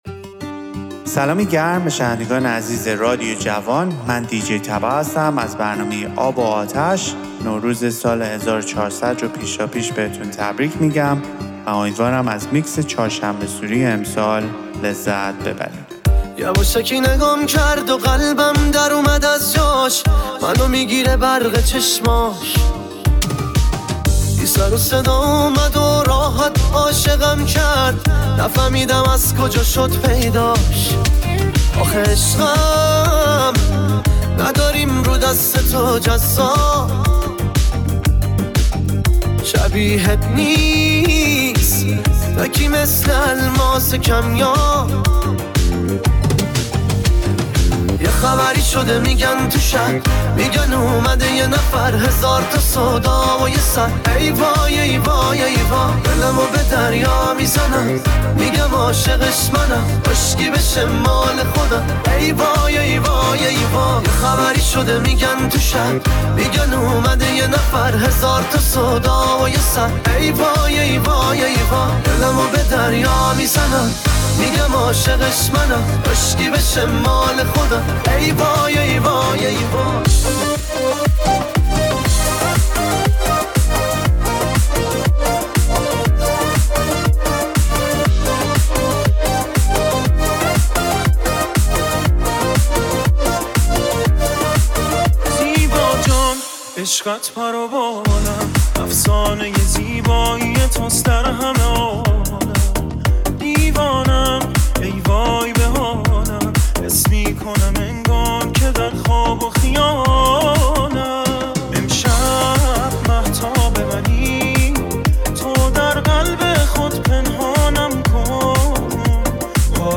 میکس آهنگ شاد چهارشنبه سوری
ریمیکس طولانی چهارشنبه سوری